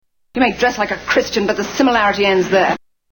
Tags: TV Series Absolutely Fabulous Comedy Absolutely Fabulous clips British